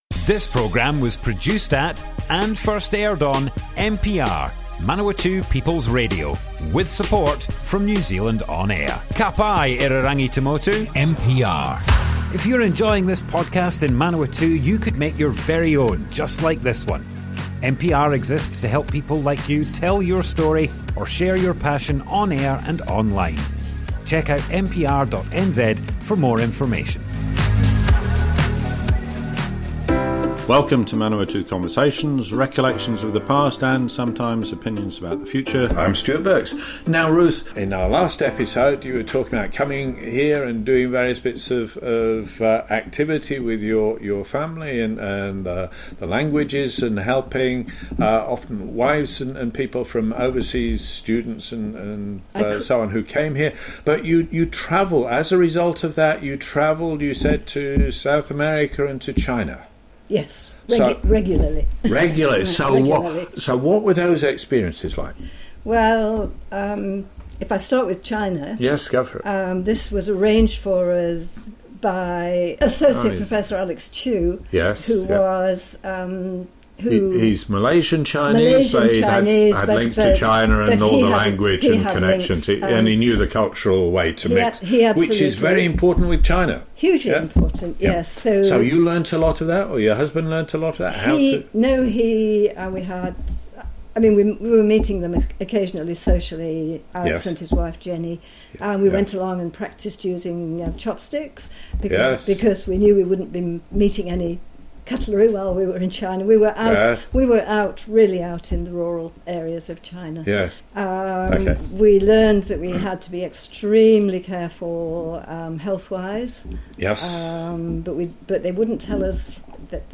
Manawatu Conversations More Info → Description Broadcast on Manawatu People's Radio, 15th July 2025.
oral history